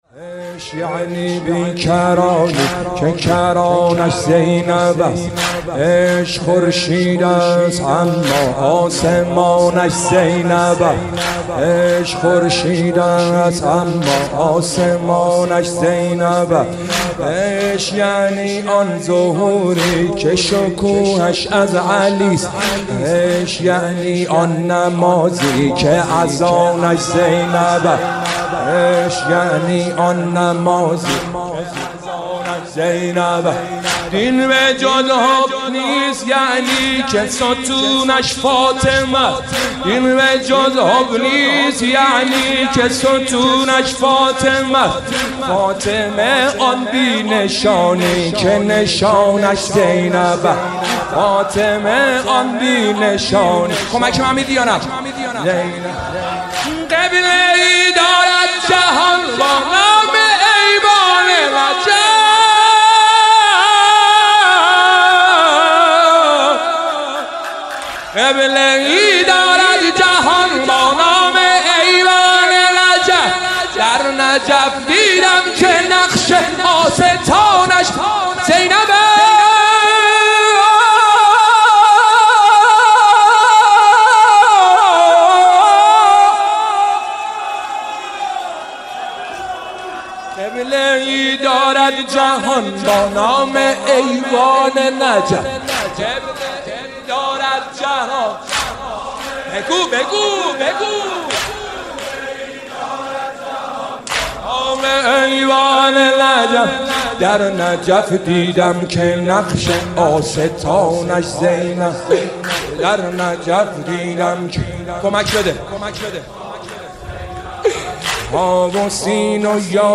• نوحه و مداحی ~ ویژه
مراسم هر شب محرم ساعت 21
( شب دوم محرم )